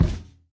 sounds / mob / irongolem / walk1.ogg
walk1.ogg